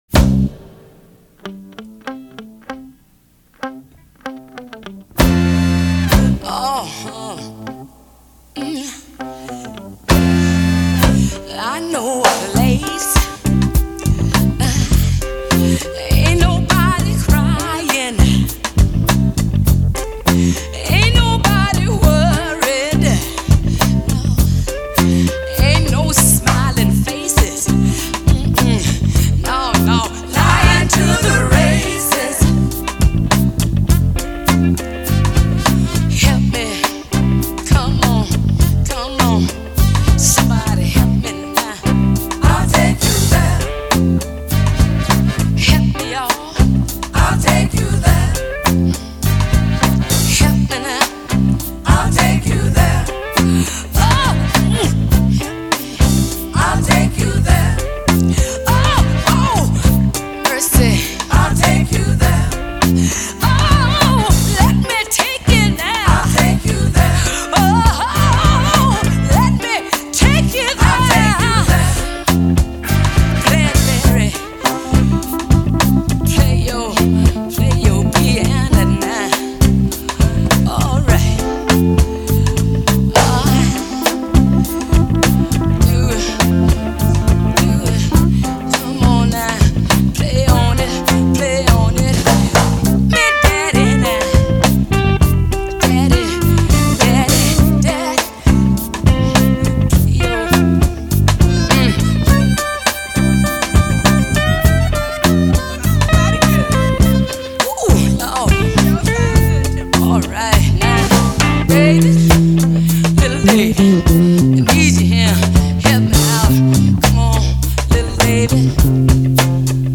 opening bass line and blast of brass
they turned in a gospel-rich pop/soul classic.